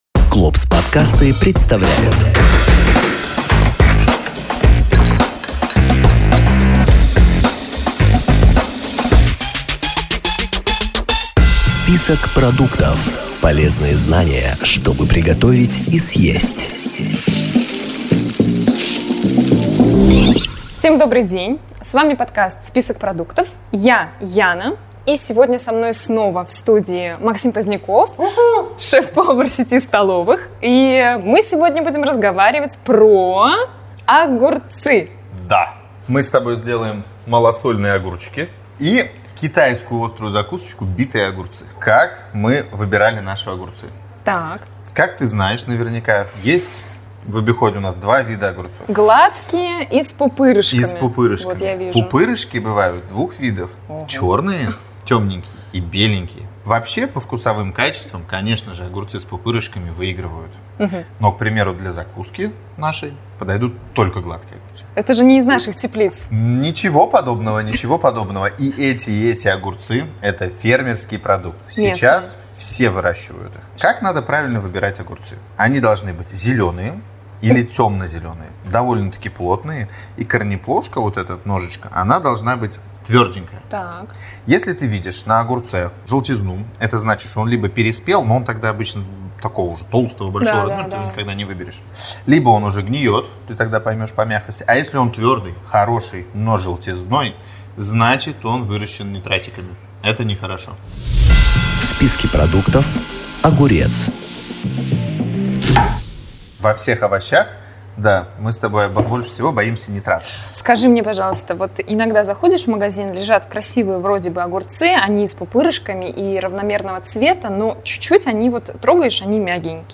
Ведущая вместе с профессиональным поваром знакомятся, обсуждают выбранный продукт и готовят из него блюдо. В процессе приготовления они говорят об основных правилах выбора, хранения и приготовления этого продукта.